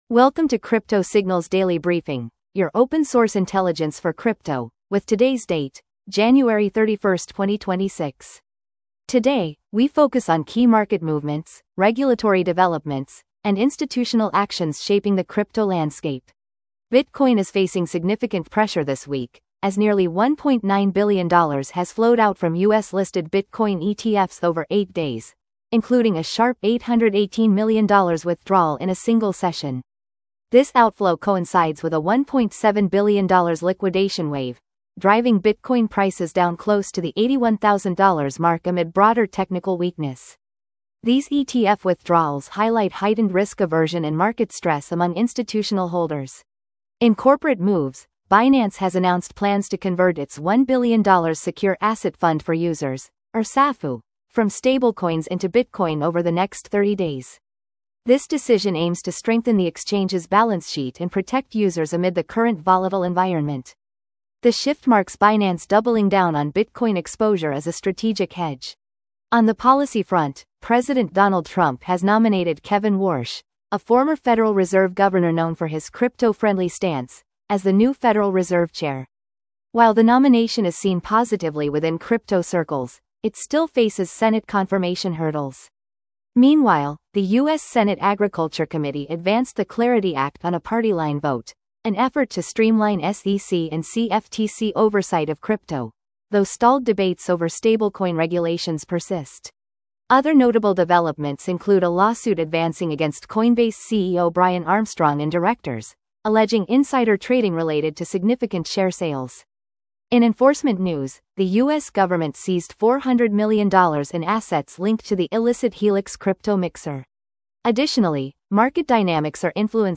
Crypto Signals Daily market brief